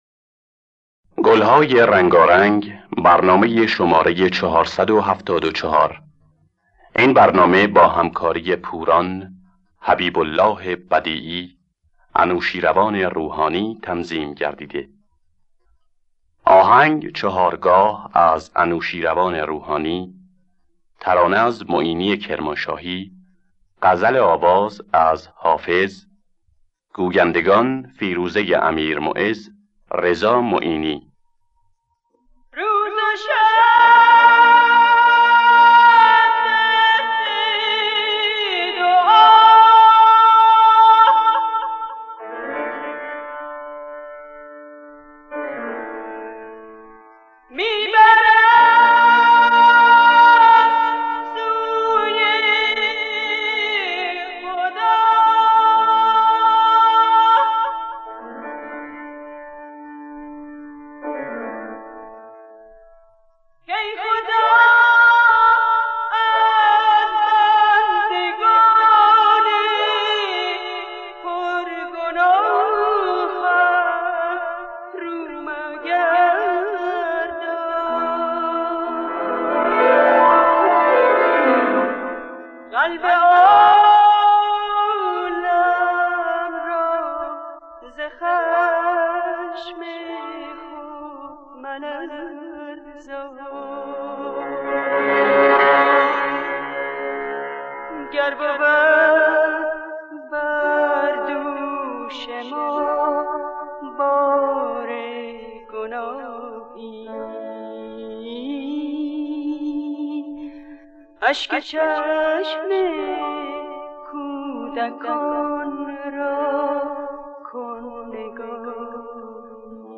دانلود گلهای رنگارنگ ۴۷۴ با صدای پوران در دستگاه چهارگاه.